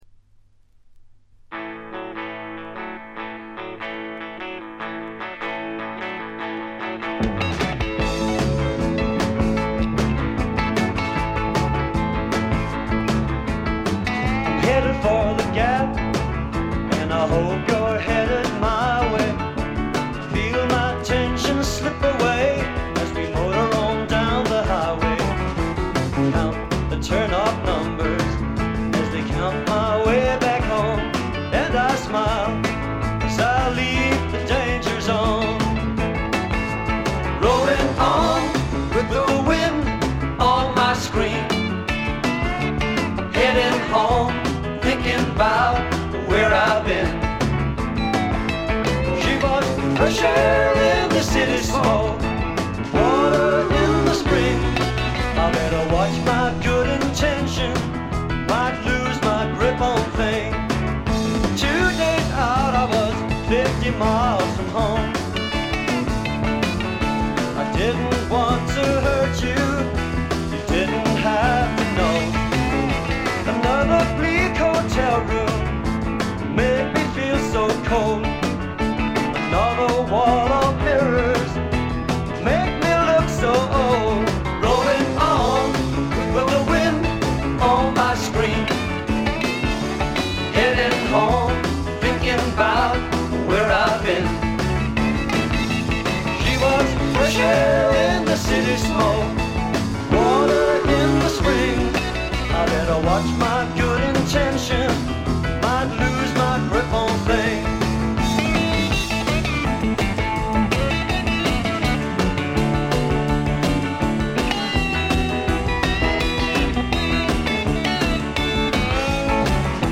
ほとんどノイズ感無し。
聴くたびにご機嫌なロックンロールに身をゆだねる幸せをつくずく感じてしまいますね。
試聴曲は現品からの取り込み音源です。